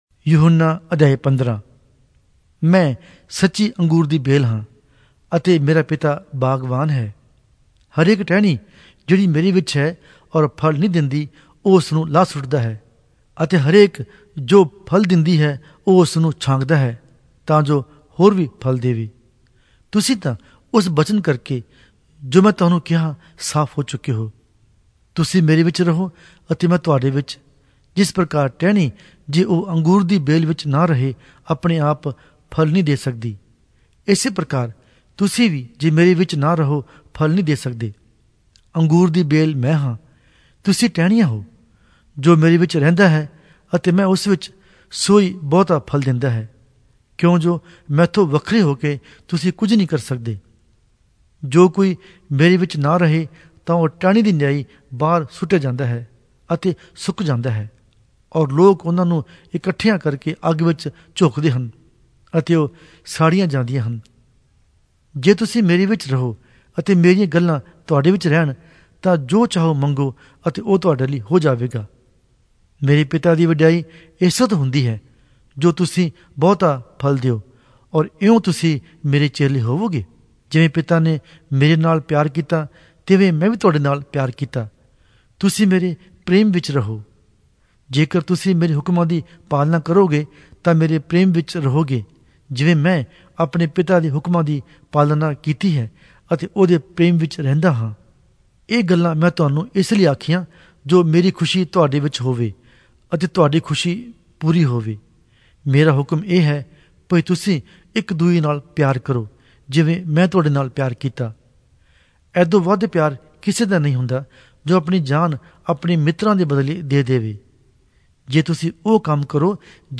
Punjabi Audio Bible - John 20 in Irvhi bible version